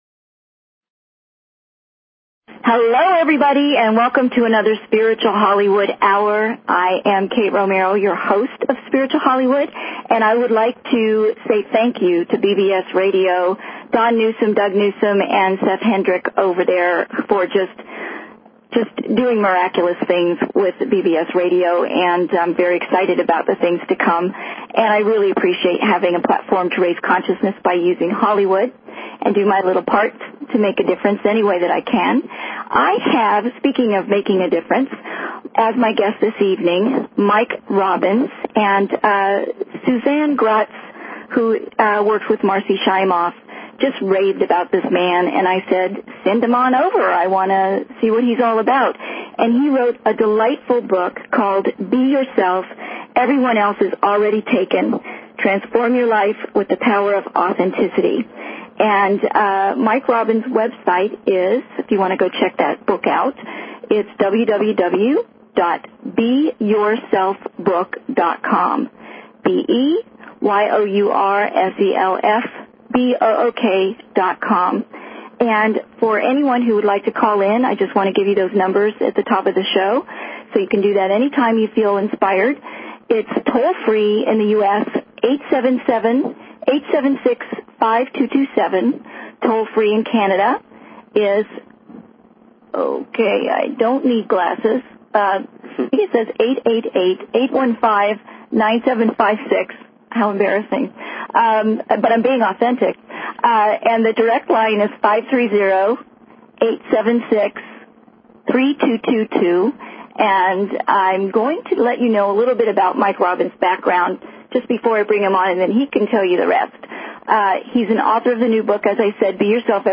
Talk Show Episode, Audio Podcast, Spiritual_Hollywood and Courtesy of BBS Radio on , show guests , about , categorized as